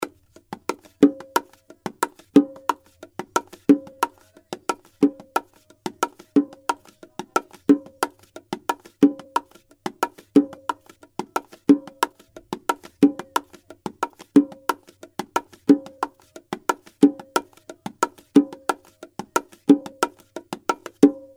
90 BPM bongo loops (11 variations)
This package contains 11 bongos loops and fills.
We recorded the bongos in very high quality sound,we used,
Qty: $0.00 Real bongo loops at 90 bpm .
All the loops are stereo 44100 Hz Wav quality.